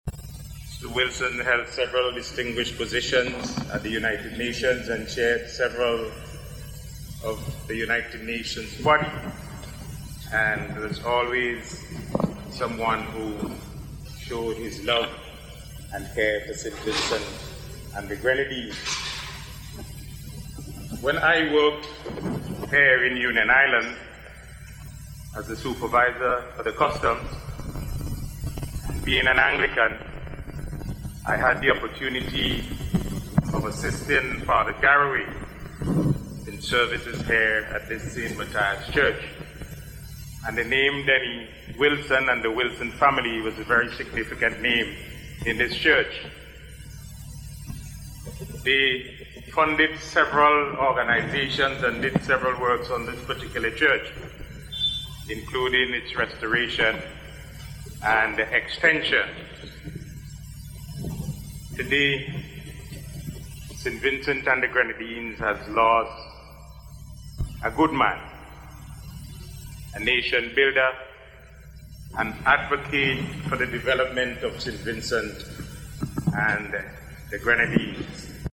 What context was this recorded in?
Officials, family members, friends and well-wishers gathered this morning at the St. Matthias Anglican Church in Union Island, to say their last farewell to the former Parliamentarian.